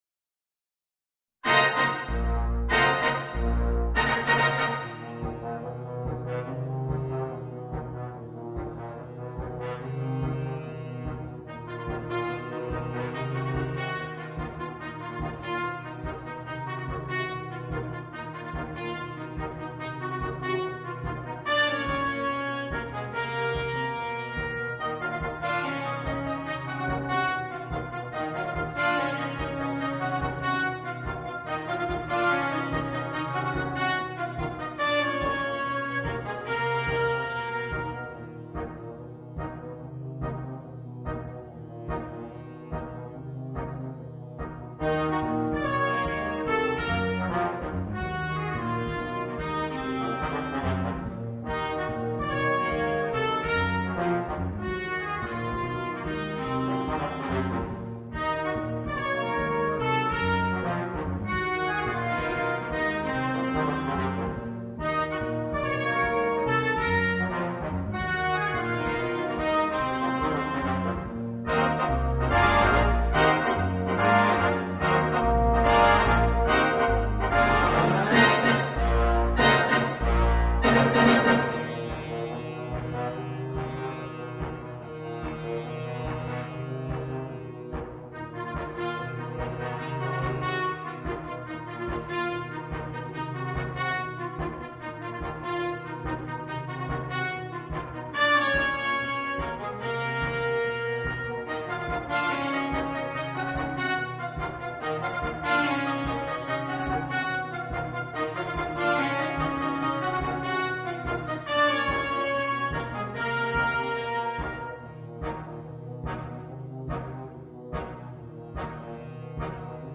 для духового оркестра